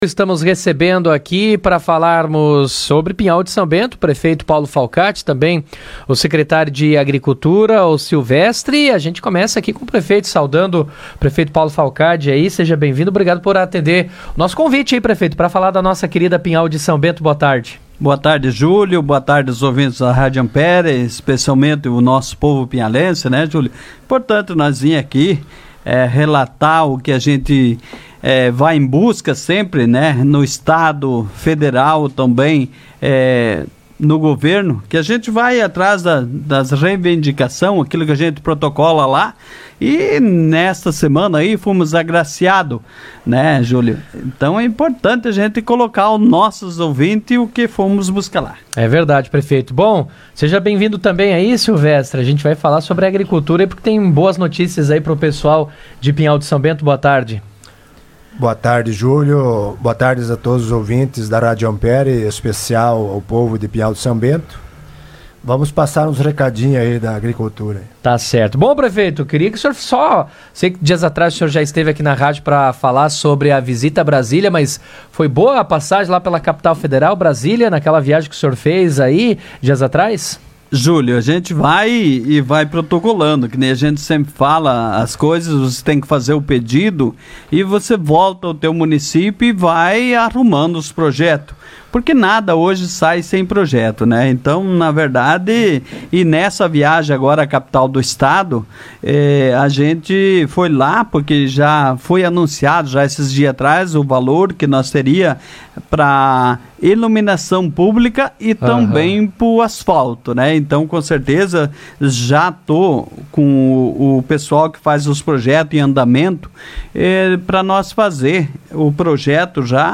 O Prefeito de Pinhal de São Bento, Paulo Falcade e o secretário de agricultura Silvestre Barcki, concederam entrevista ao vivo no Jornal RA 2ª Edição desta quinta-feira, 06.